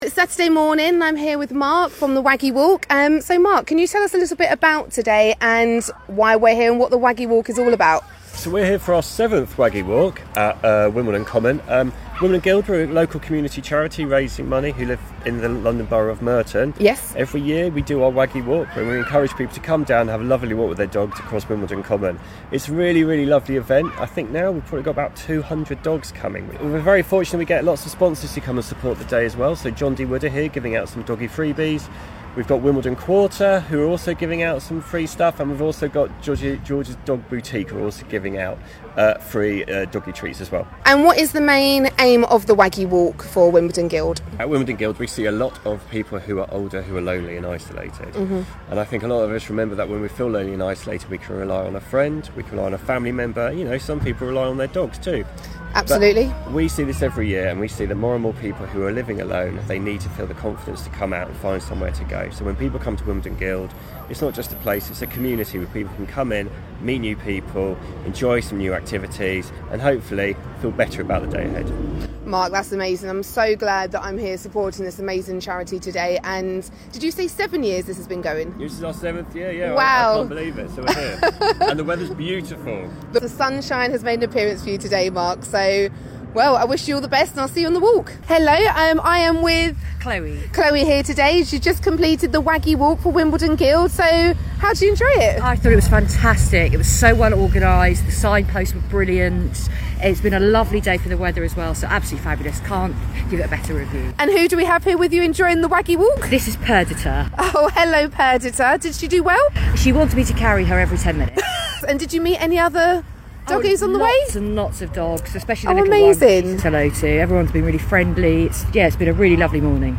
reports from the 7th annual Waggy Walk for Wimbledon Guild.